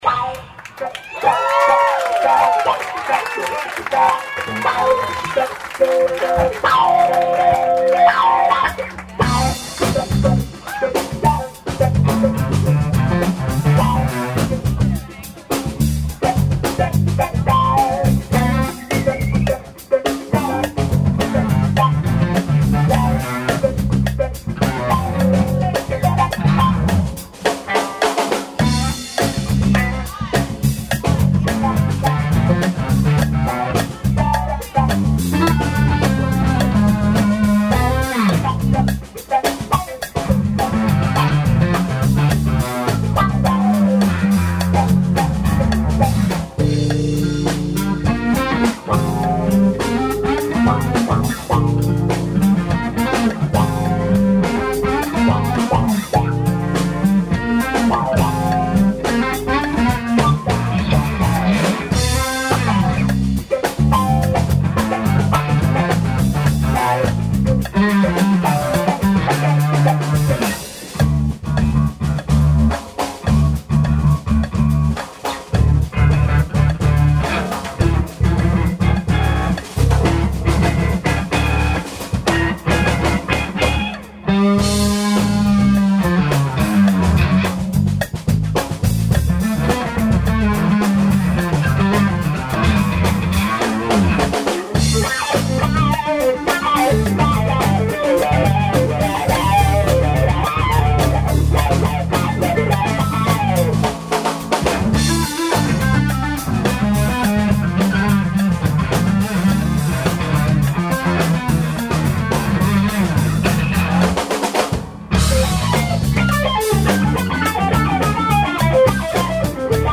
The Baked Potato, Hollywood, CA
guitar
drums
lead bass
anonymous audience member - unnecessary cowbell